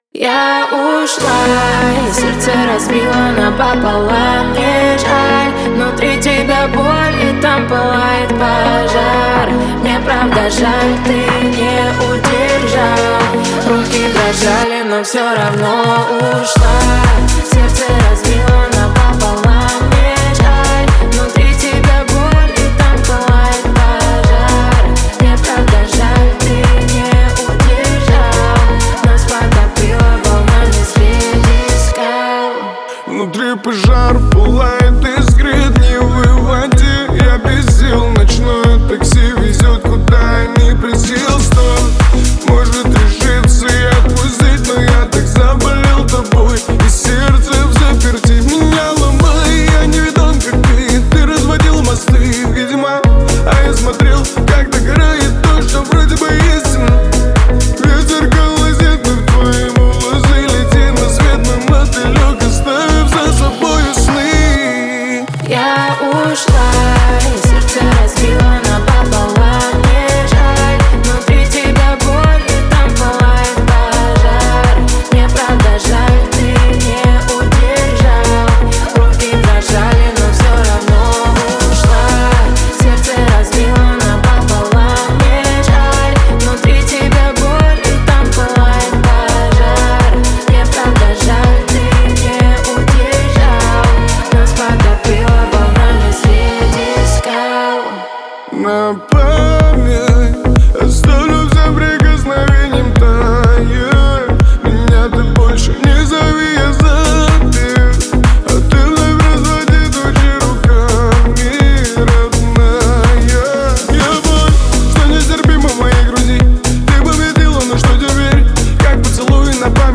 Жанр: Казахские